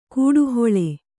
♪ kūḍu hoḷe